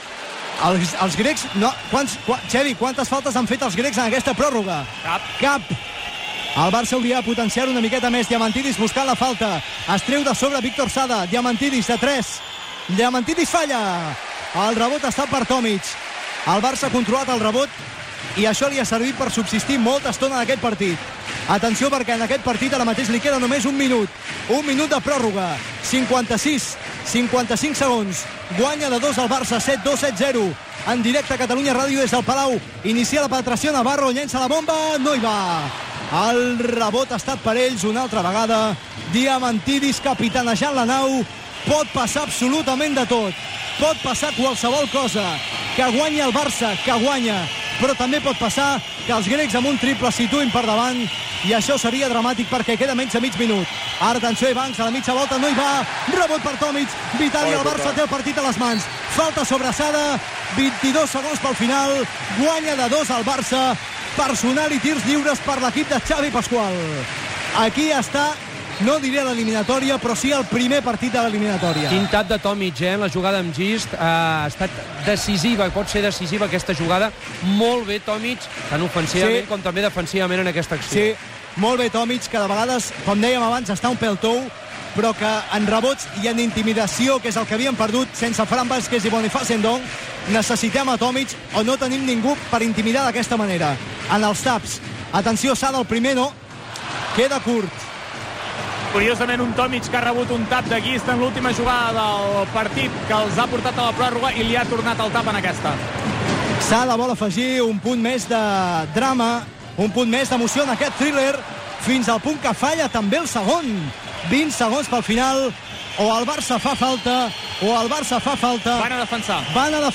Transmissió de la part final de la pròrroga del partit de l'Eurolliga de bàsquet masculí Regal F.C. Barcelona - Panathinaikos
Esportiu